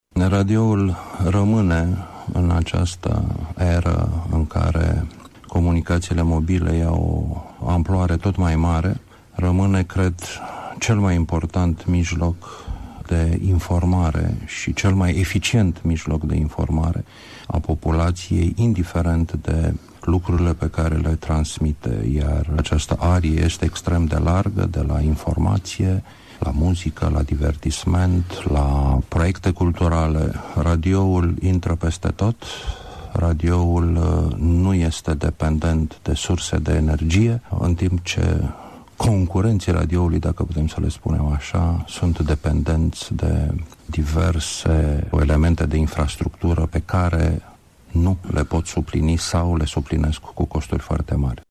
Preşedintele director general al Radiodifuziunii Române, Ovidiu Miculescu: